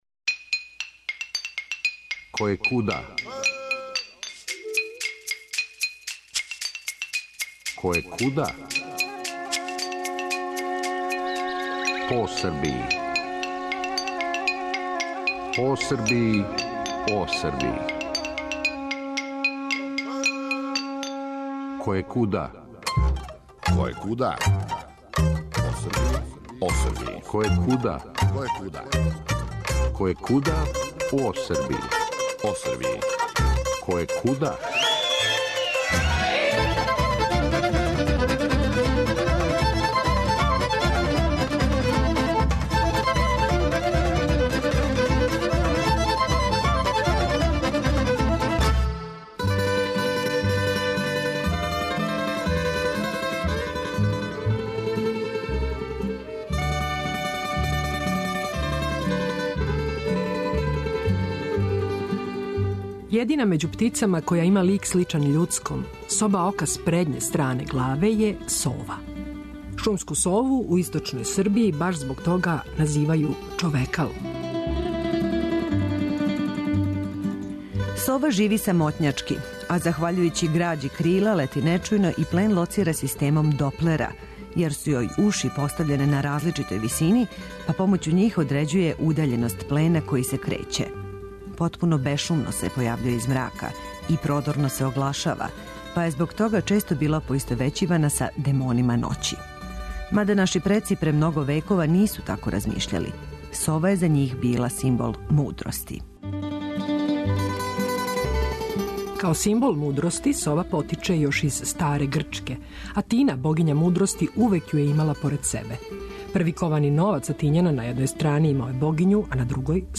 Зато смо тамо и забележиле ову нашу Којекуда причу.